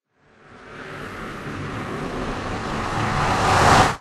anvil_break.ogg